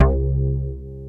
ANALBAS4C2-L.wav